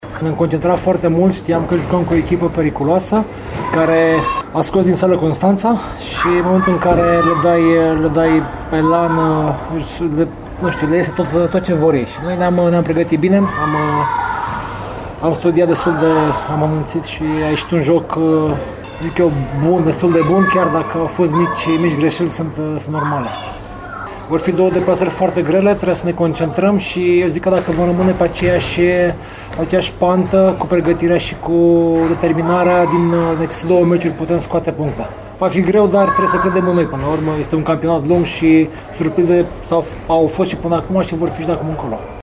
Din declarațiile de final